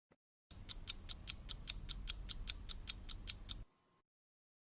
Free Foley sound effect: Clock Ticking.
Clock Ticking
278_clock_ticking.mp3